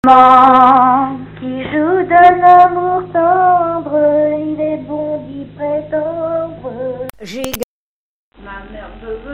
scottish à sept pas
Chants brefs - A danser
Pièce musicale inédite